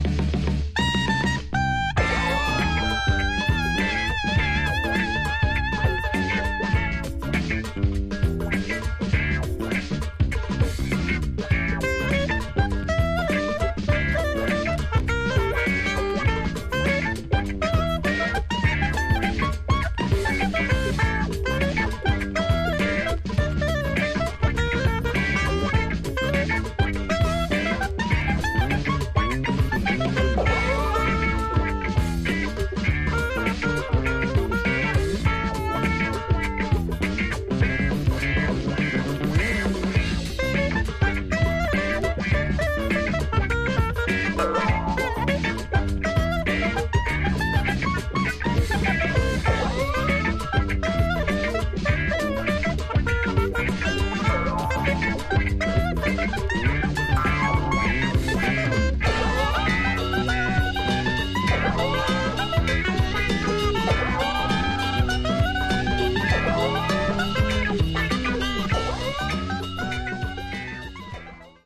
Super psicodelic sounds